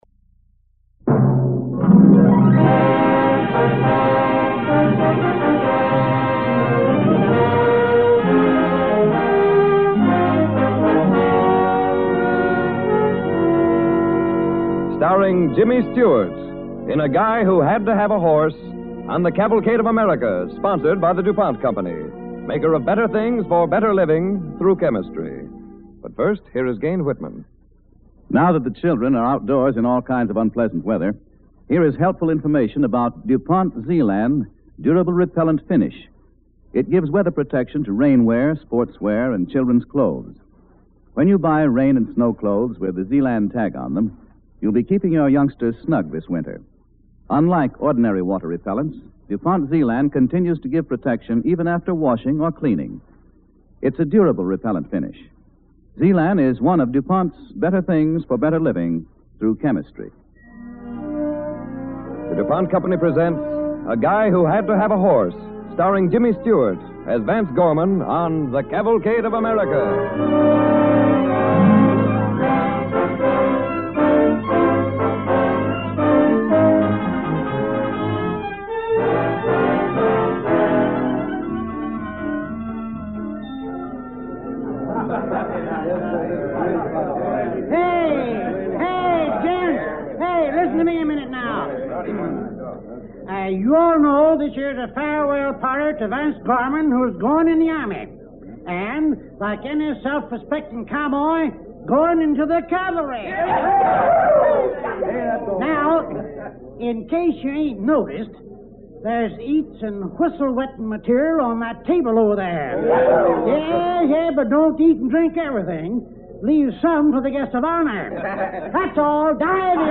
A Guy Who Had to Have a Horse, starring James Stewart and Herb Vigran